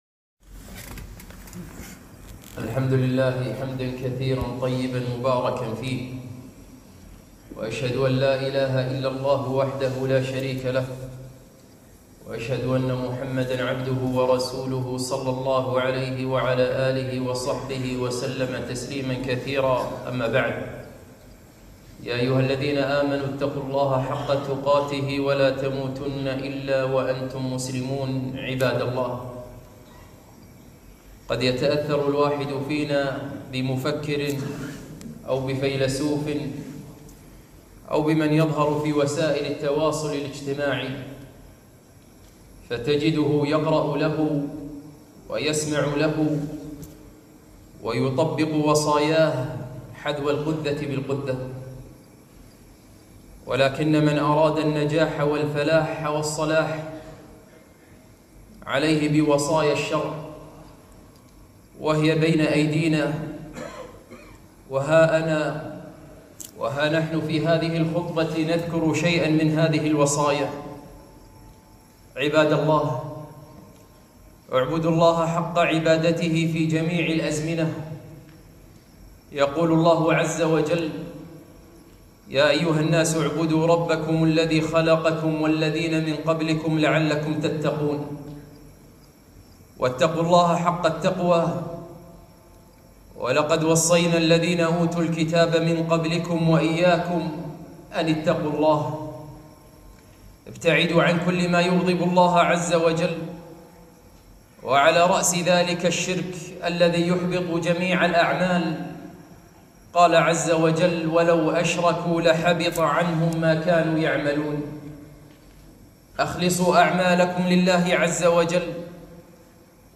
خطبة - أجمل ما قيل في الوصايا